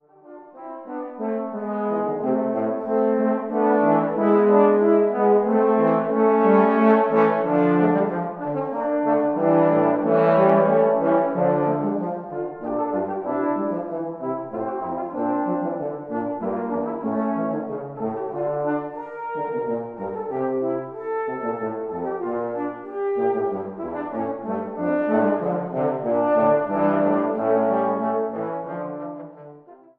Leipziger Intermezzo für vier Posaunen